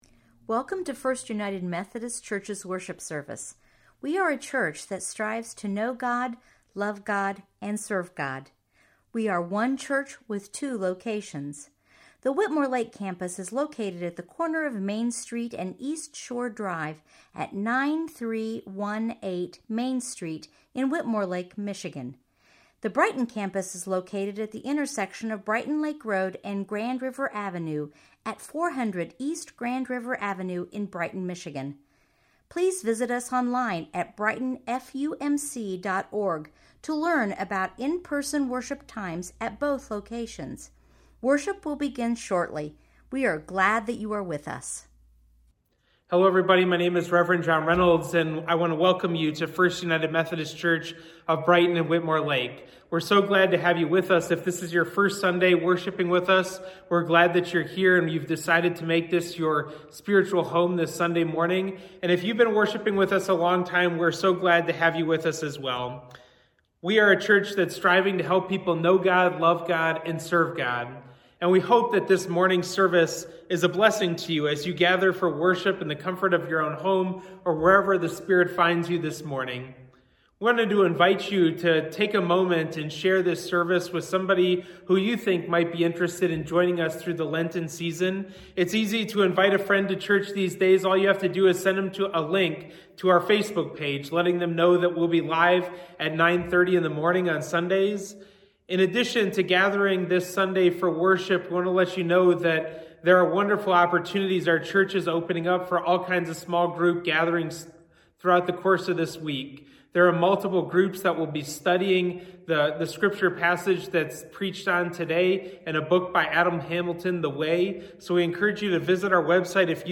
preaches on Matthew 3:13-17 & Matthew 4:1-11